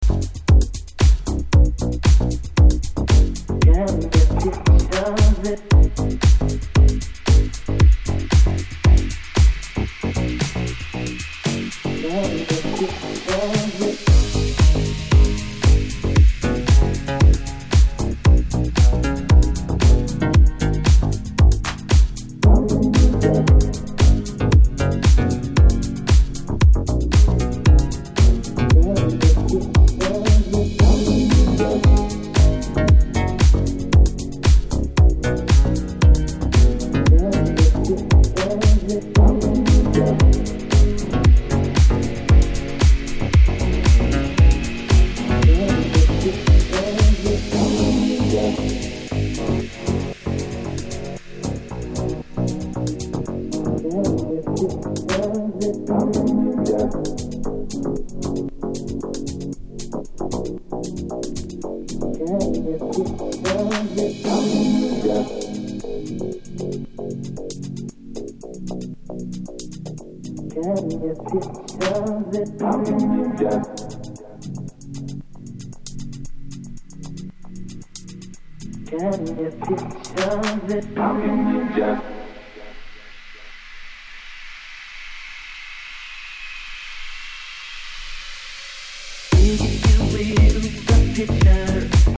The A-side is modern funk house with an ennui atmosphere